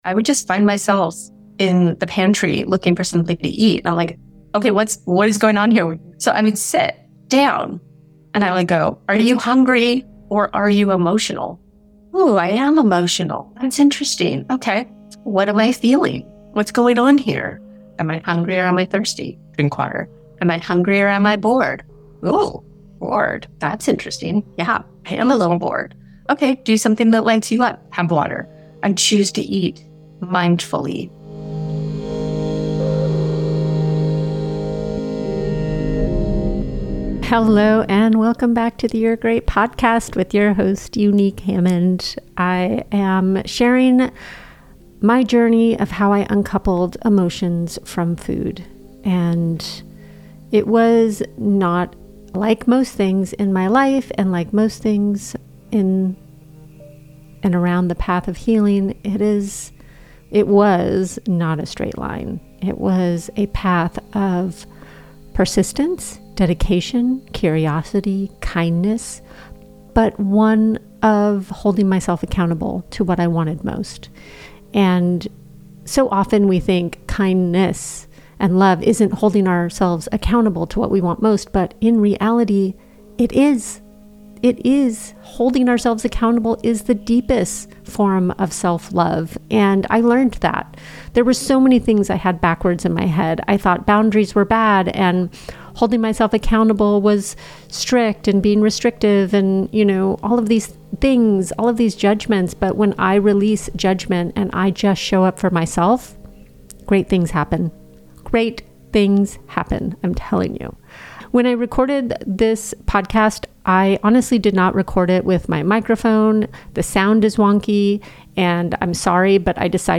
Podcast 45 - Solo Podcast.